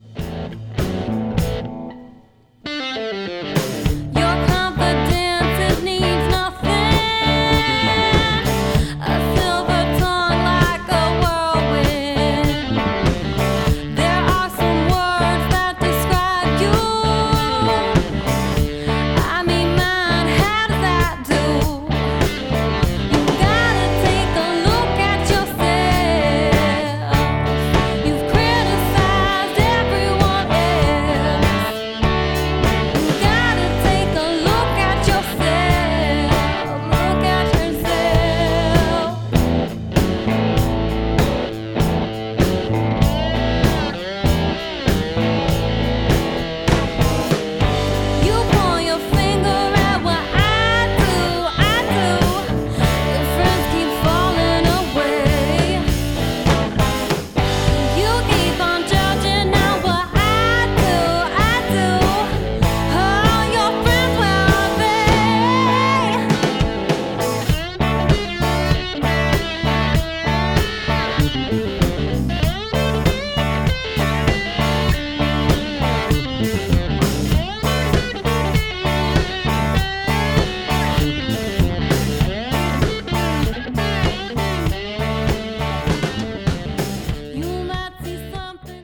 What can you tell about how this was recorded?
An original Recorded at Third Ward Records February 2015.